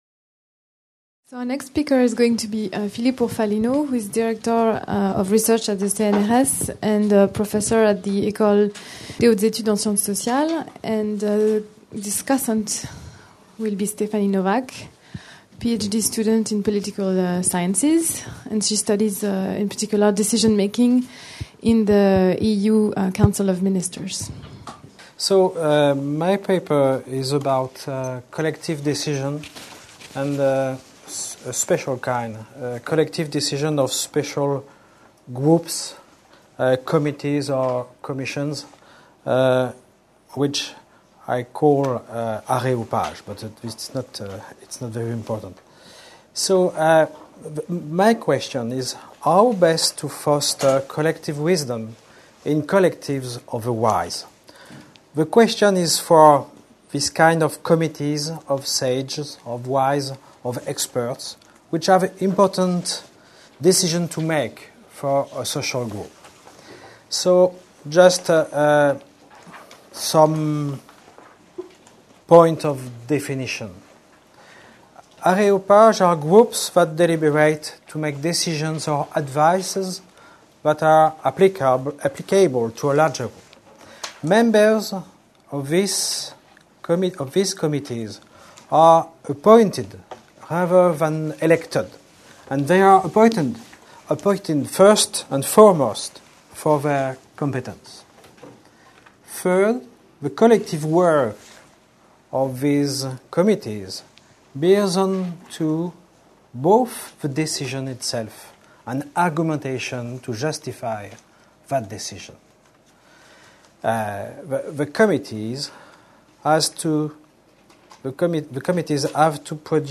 La sagesse collective : principes et mécanismes Colloque des 22-23 mai 2008, organisé par l'Institut du Monde Contemporain du Collège de France, sous la direction du Professeur Jon Elster.